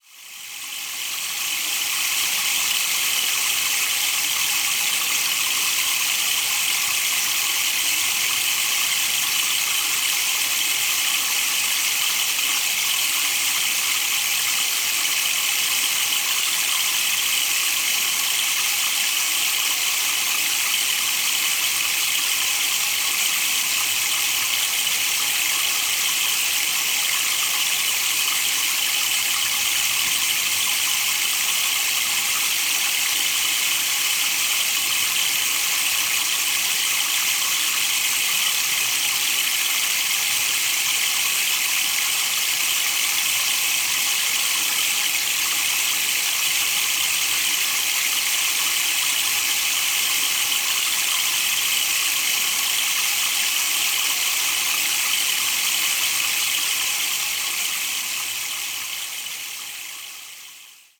Der Meinl Sonic Energy Rainmaker ist aus hochwertigem, lackiertem Holz gefertigt und erzeugt einen beruhigenden, natürlichen Regensound, der tief…
Seine sanften, fließenden Klänge machen ihn zum perfekten Klanghintergrund für Klangbäder, Klangreisen, Yoga-Sessions und Meditationen, wobei gleichzeitig andere Instrumente gespielt werden können.